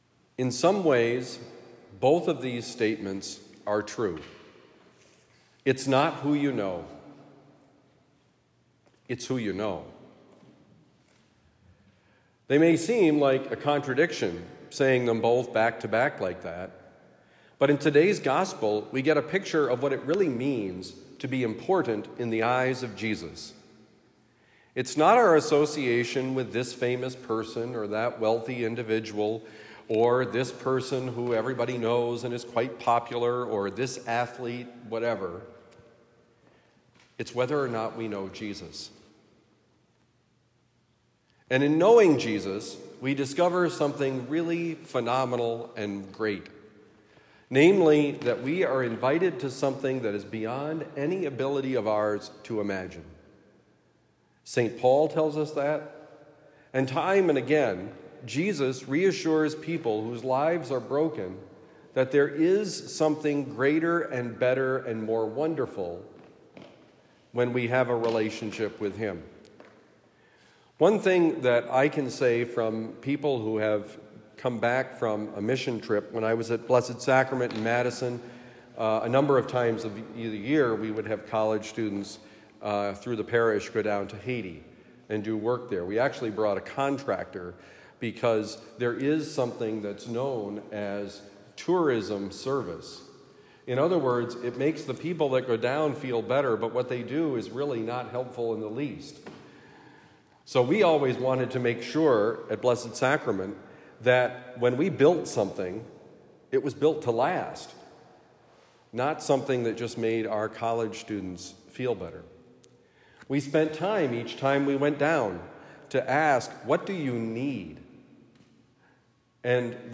A mess until it wasn’t: Homily for Monday, July 22, 2024